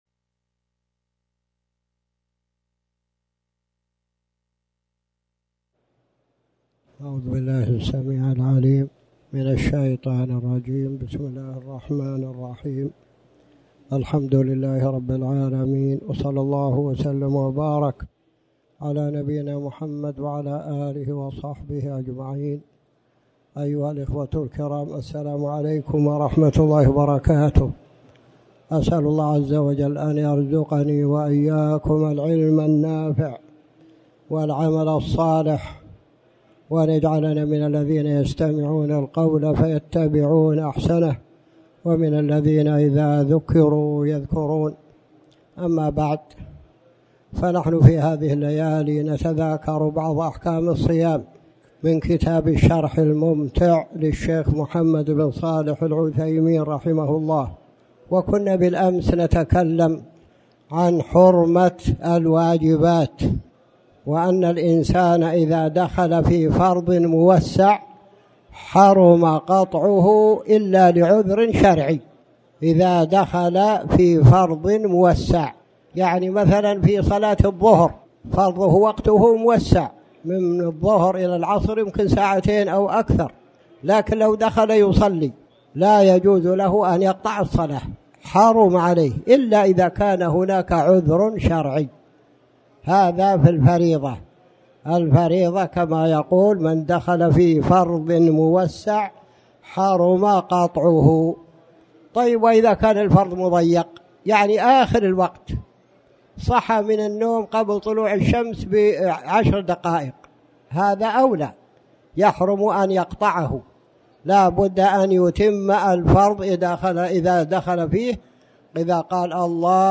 تاريخ النشر ١٦ شعبان ١٤٣٩ هـ المكان: المسجد الحرام الشيخ